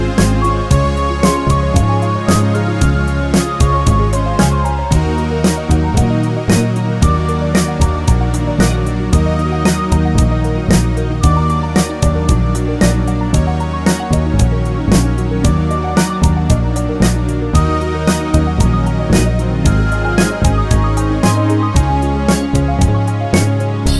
no Backing Vocals Soul / Motown 4:20 Buy £1.50